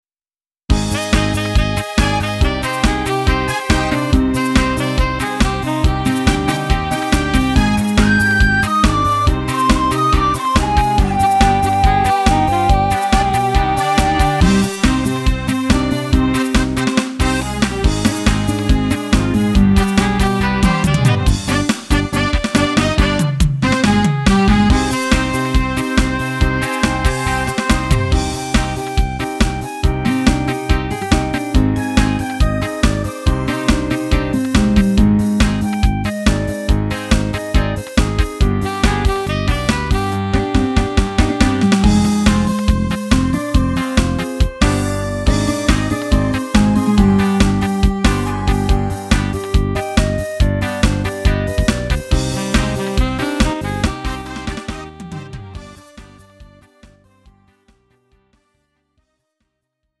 음정 원키 3:37
장르 가요 구분 Lite MR
Lite MR은 저렴한 가격에 간단한 연습이나 취미용으로 활용할 수 있는 가벼운 반주입니다.